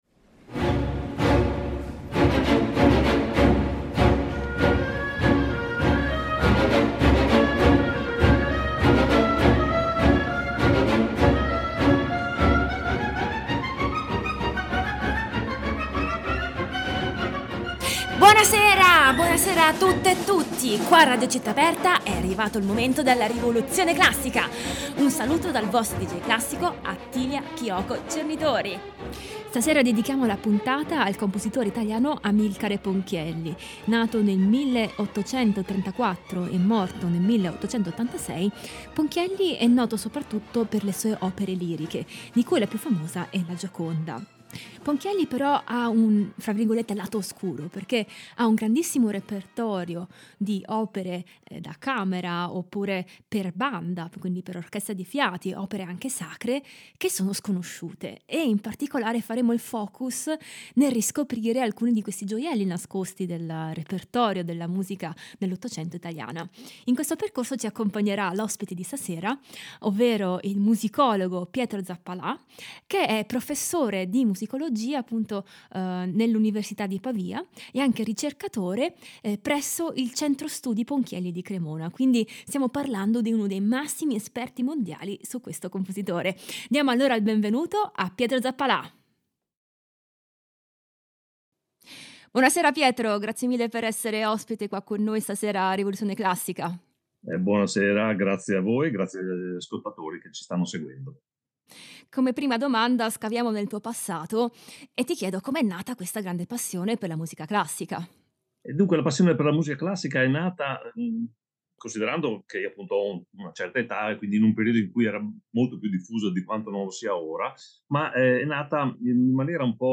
NBC Symphony Orchestra Arturo Toscanini, direttore d’orchestra
per violoncello e pianoforte
per violino e pianoforte